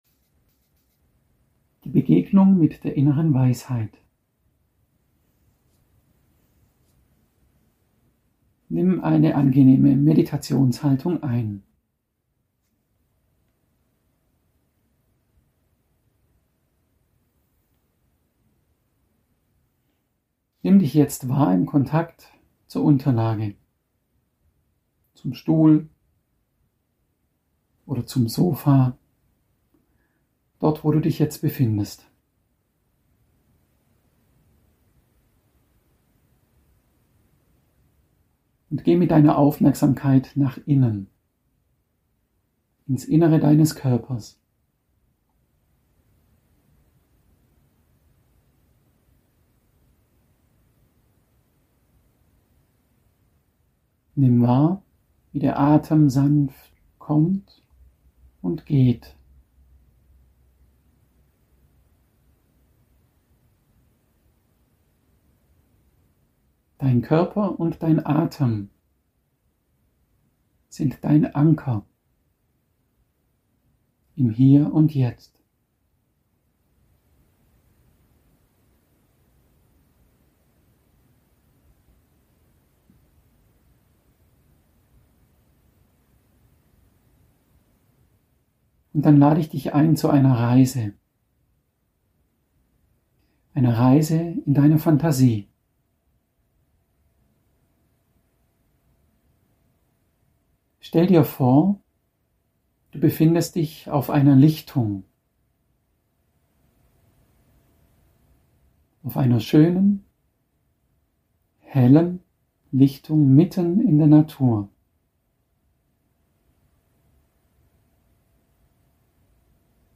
In dieser Phantasiereise erleben Sie eine heilsame Begegnung mit Ihrer ganz persönlichen inneren Weisheit.